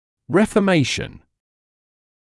[ˌrefə’meɪʃn][ˌрифэ’мэйшн]преобразование, изменение